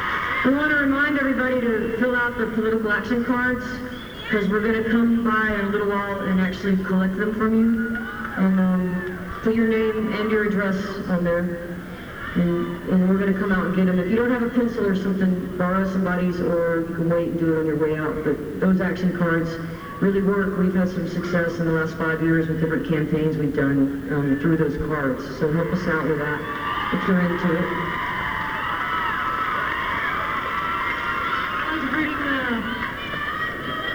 lifeblood: bootlegs: 1997-09-21: township auditorium - columbia, south carolina